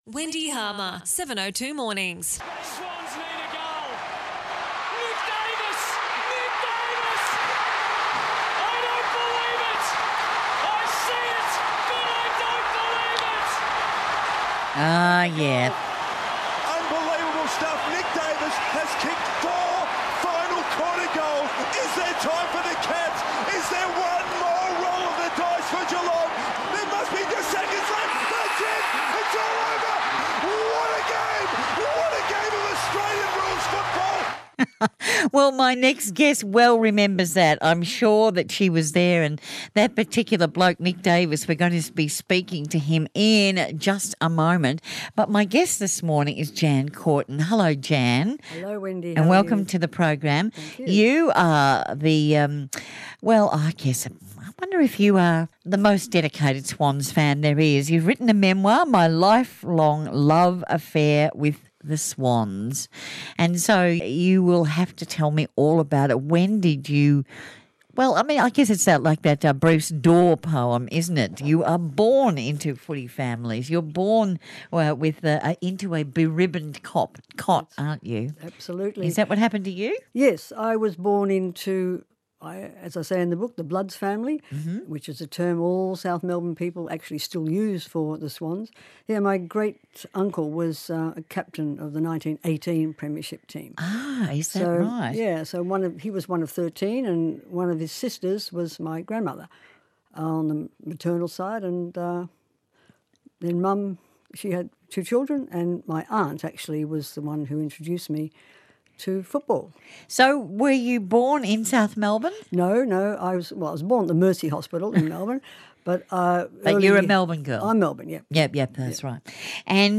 speaks with Wendy Harmer on ABC Radio.